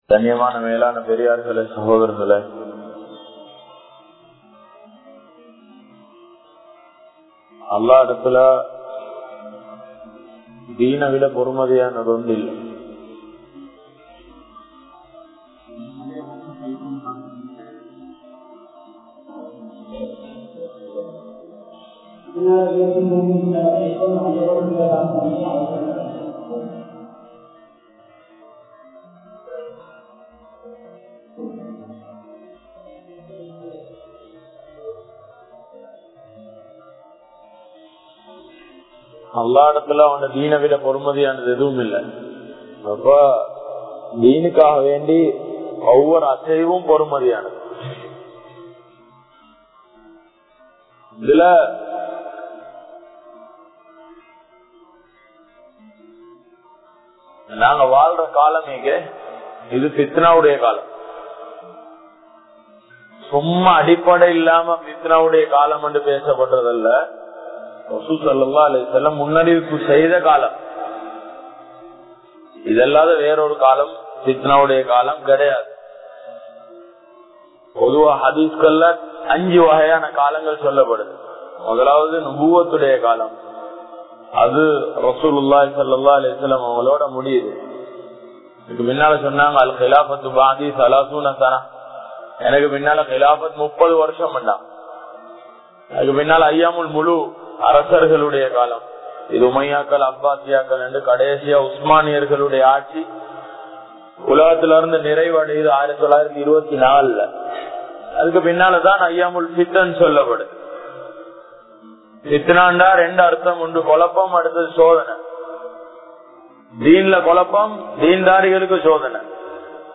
Naam Vaalum Kaalam Fithnaavudaiyathaa? (நாம் வாழும் காலம் பித்னாவுடையதா?) | Audio Bayans | All Ceylon Muslim Youth Community | Addalaichenai
Kollupitty Jumua Masjith